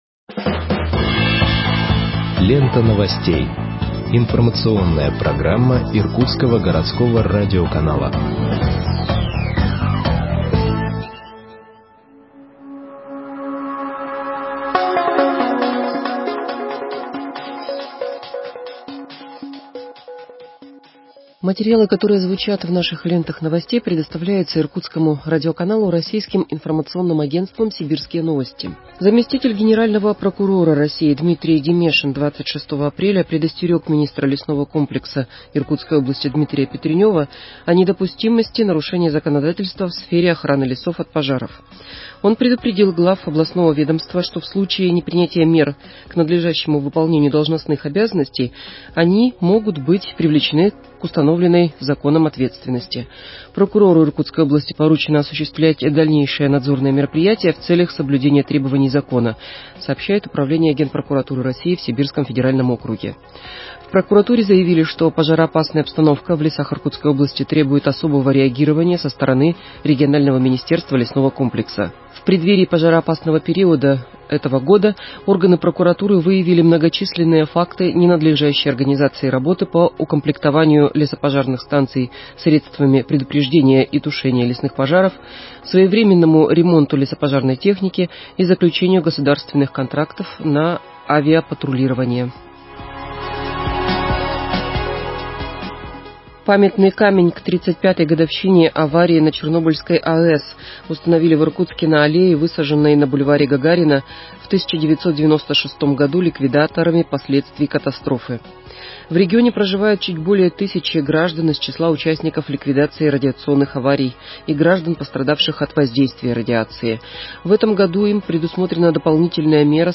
Выпуск новостей в подкастах газеты Иркутск от 27.04.2021 № 2